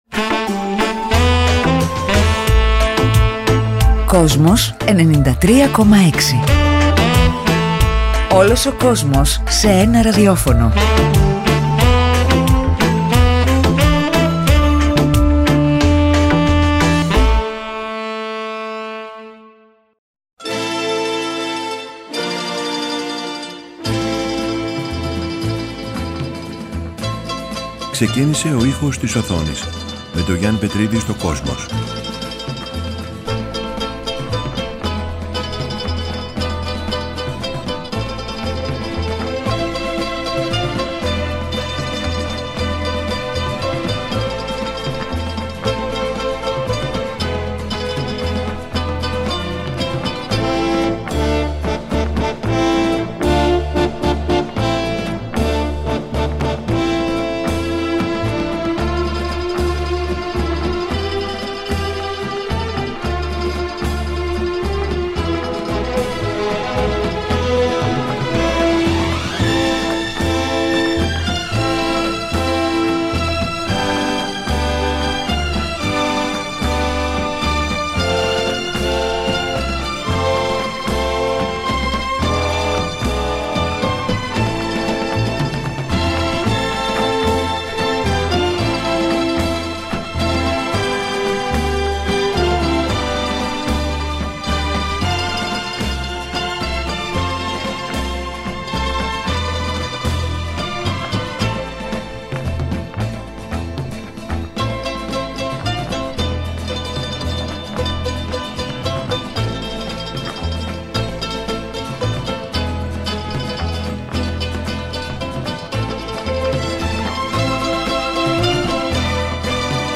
Μεταξύ άλλων ακούγεται μουσική από τα φιλμ